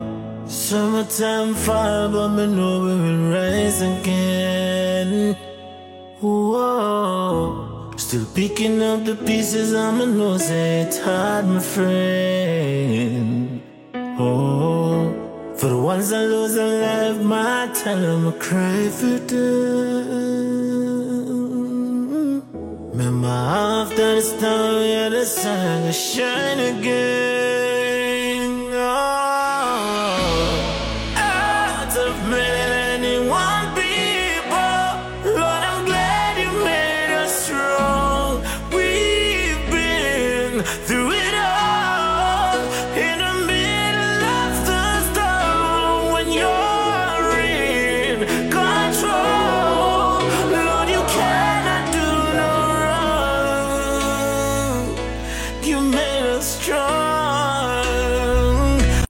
Within Nigeria’s ever-evolving Afrobeats movement